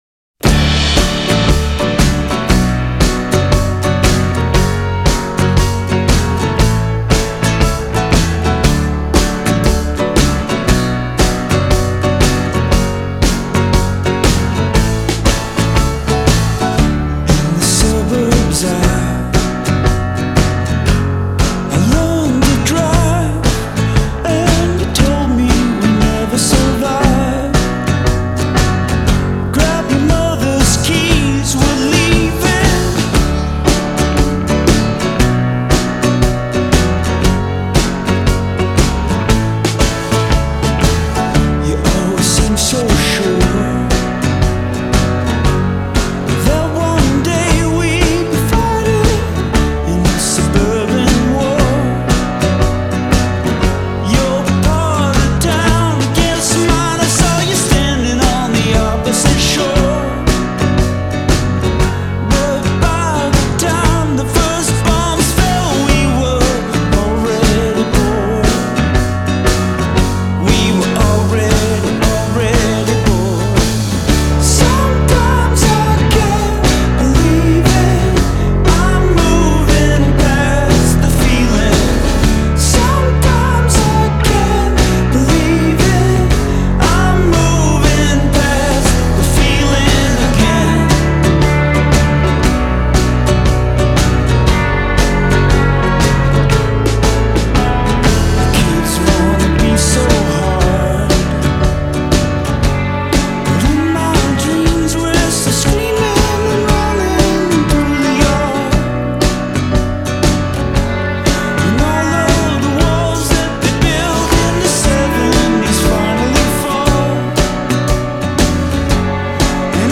I’m especially digging the groovy rhythms of this new album.